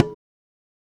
African Drum_01.wav